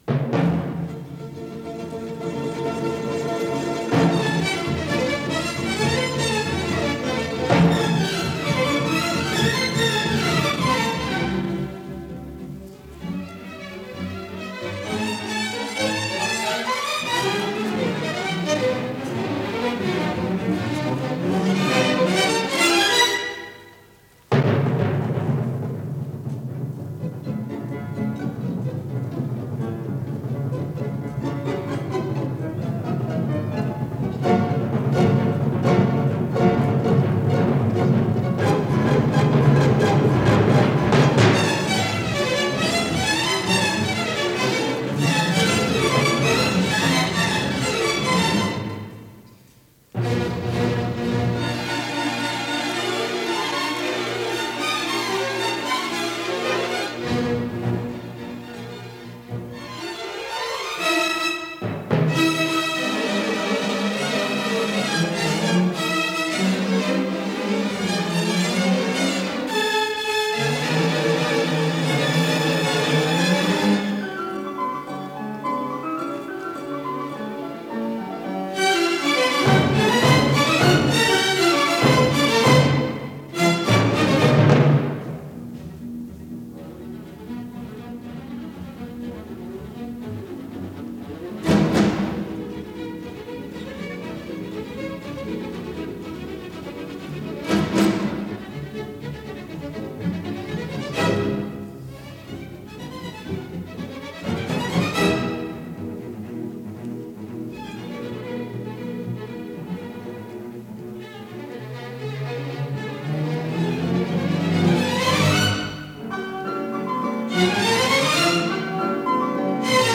Аллегро мольто
ИсполнителиСимфонический оркестр Ленинградской Государственной филармонии
Дирижёр - Евгений Мравинский